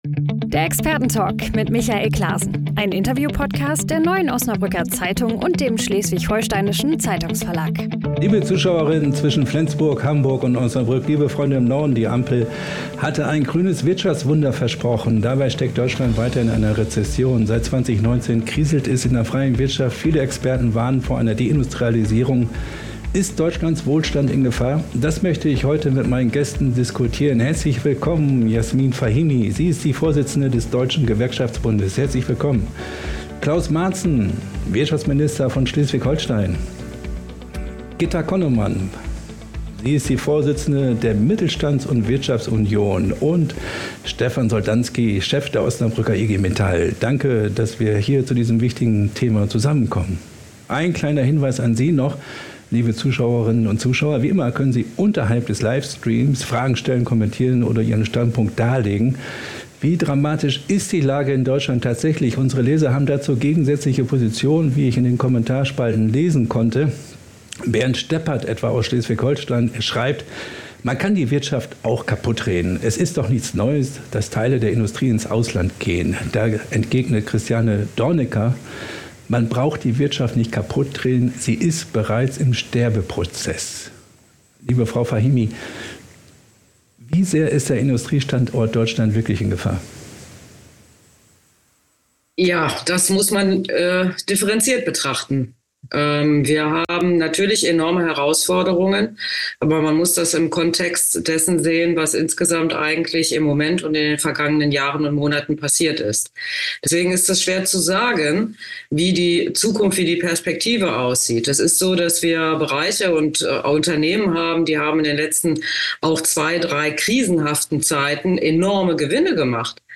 Darüber diskutieren die Vorsitzende der Mittelstandsunion Gitta Connemann, DGB-Chefin Yasmin Fahimi und der schleswig-holsteinische Wirtschaftsminister Claus Ruhe Madsen im Expertentalk.